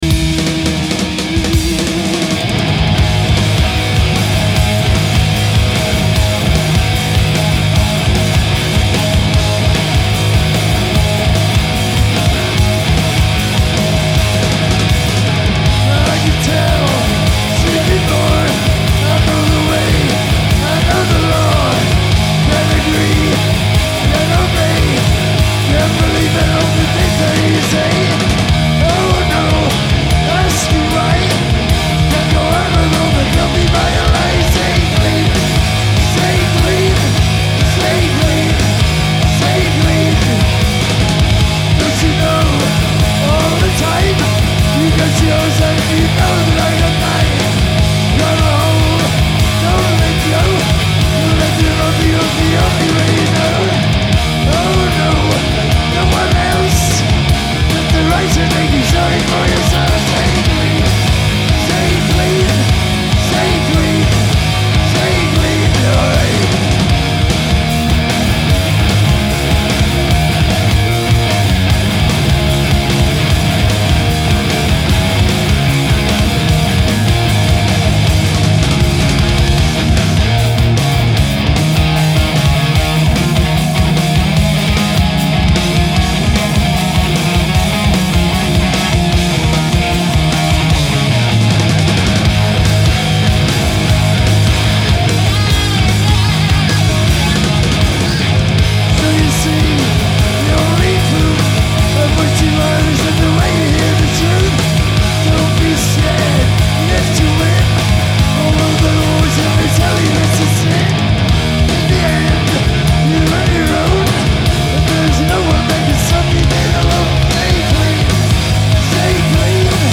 Hi-Res Stereo
Genre : Rock